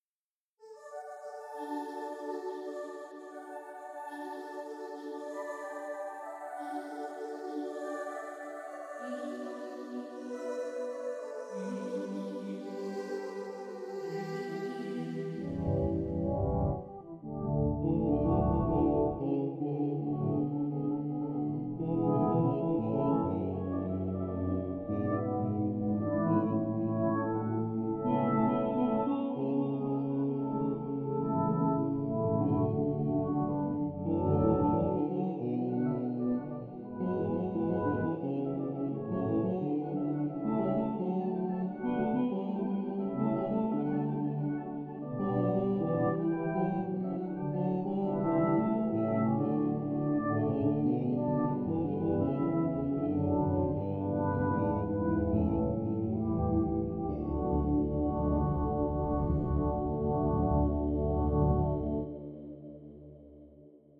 Diverse Instrumente wurden so verändert, das sie an Stimmen erinnern.
Hier zwei Beispiele der Augument Voices. mit verschiedenen Instrumenten.
arturia-auguments-strings-2.mp3